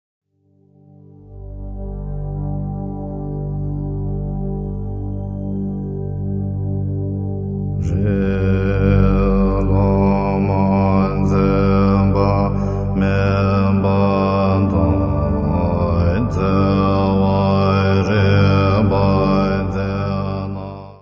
: stereo; 12 cm